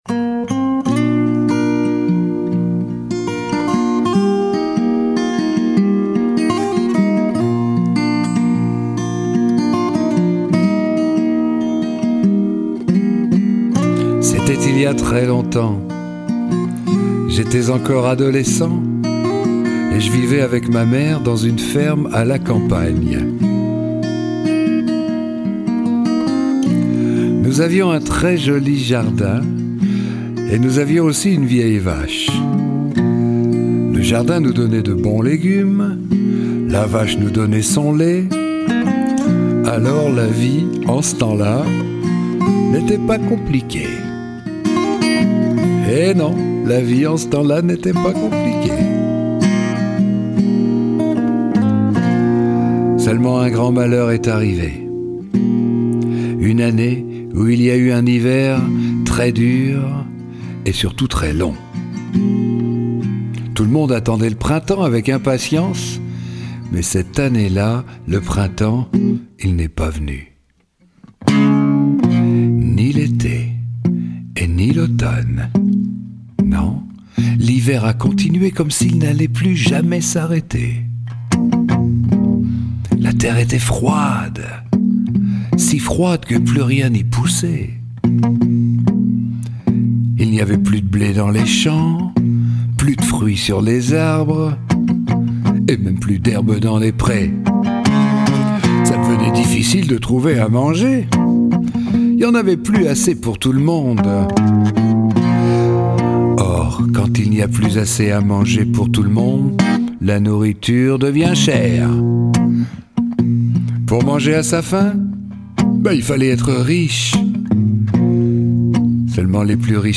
Diffusion distribution ebook et livre audio - Catalogue livres numériques
Une version inédite, contée, chantée, rythmée par une guitare imprévisible, des aventures rebondissantes d’un garçon qui accède au « monde d’en haut » en grimpant sur un haricot géant.